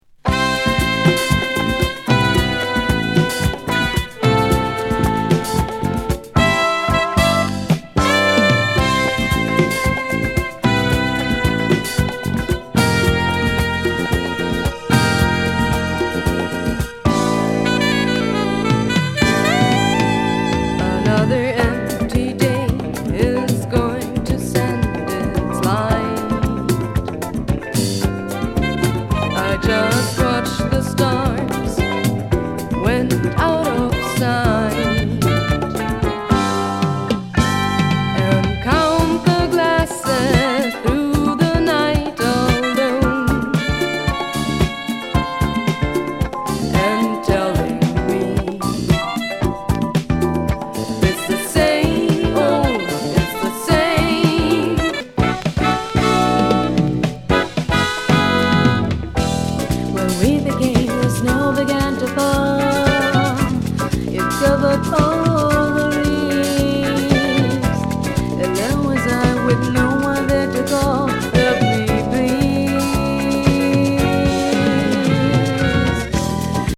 ナイスなソフト・ソウル・フュージョンAOR！